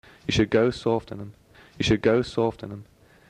this individual with strong GOAT-diphthongisation.
GOAT_diphthongisation_(strong-male_speaker).mp3